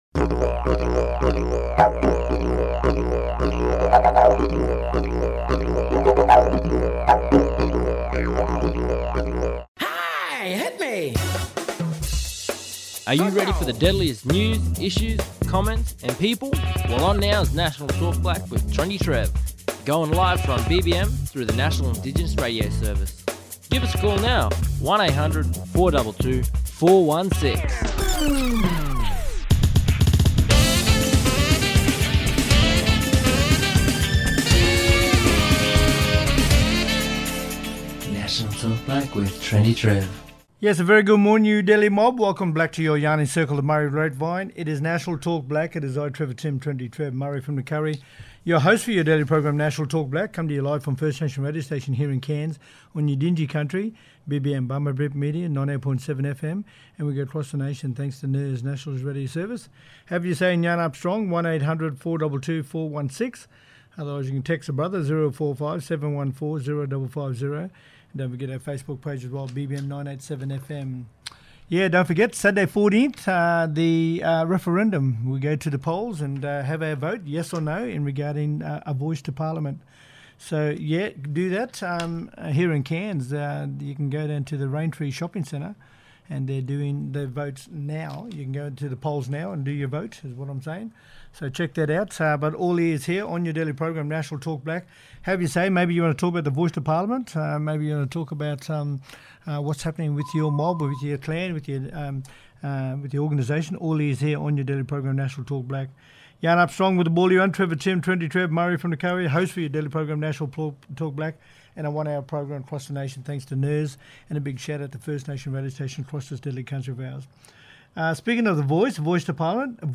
Pat Anderson AO, Co-Chair The Uluru Statement from the Heart, talking about how the Voice information has been released in 30 First Nations languages. The Uluru Dialogue has worked with translation services in Queensland, Western Australia and the Northern Territory to translate information about the referendum, what the Voice is and encourage people in these communities to vote.